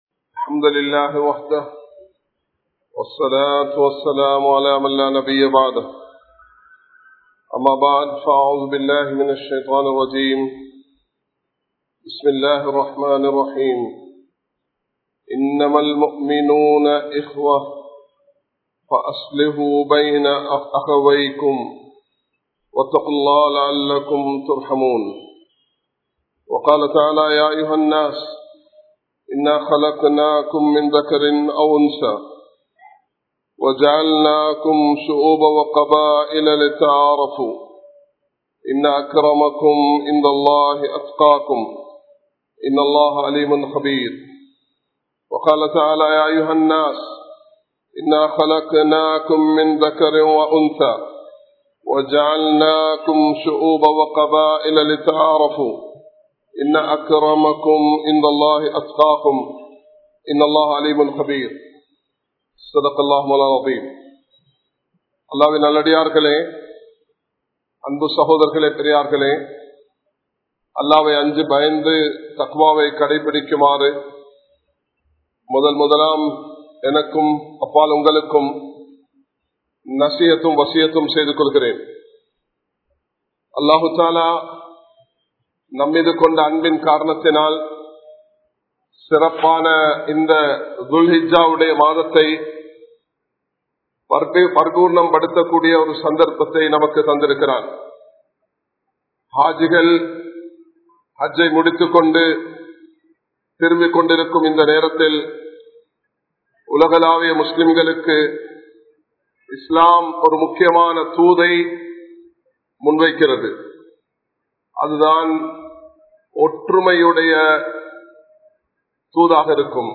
Mattravarhalai Mathiungal (மற்றவர்களை மதியுங்கள்) | Audio Bayans | All Ceylon Muslim Youth Community | Addalaichenai
Majma Ul Khairah Jumua Masjith (Nimal Road)